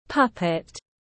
Con rối tiếng anh gọi là puppet, phiên âm tiếng anh đọc là /ˈpʌp.ɪt/
Puppet /ˈpʌp.ɪt/